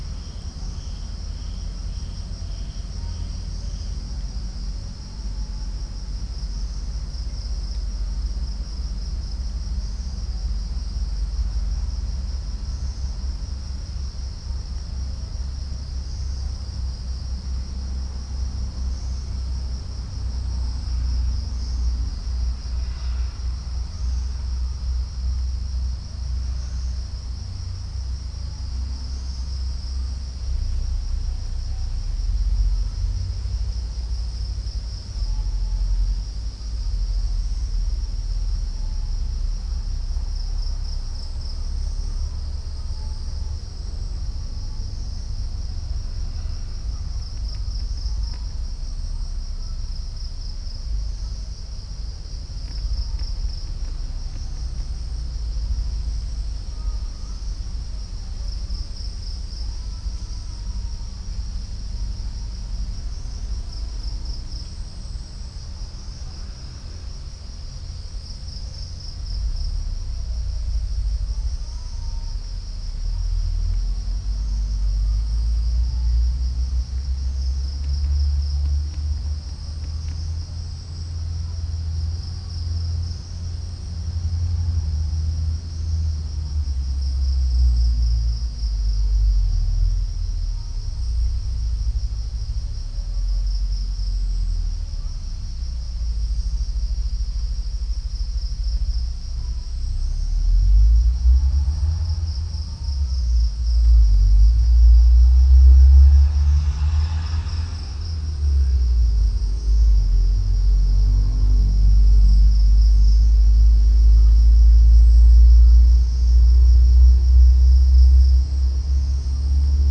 Halcyon smyrnensis
Rallina eurizonoides
Ducula aenea
Strix leptogrammica